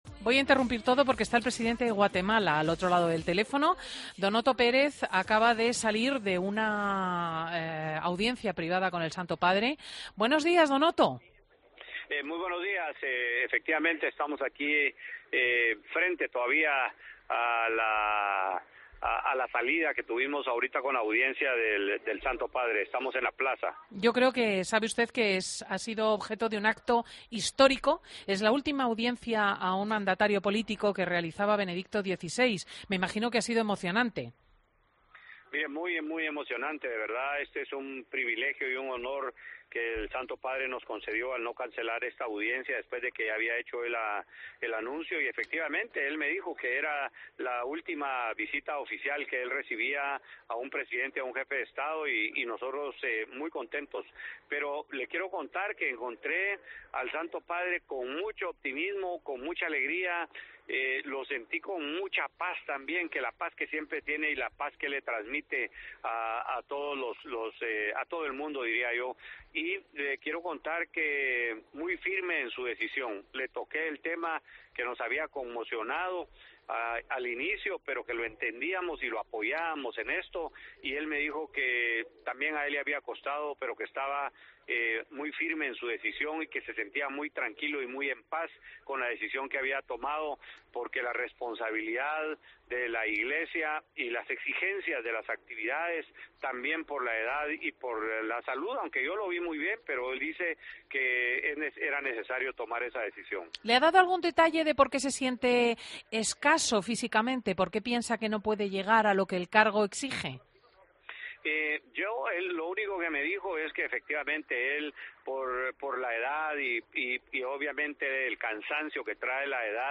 Entrevista con el Presidente de Guatemala, Otto Pérez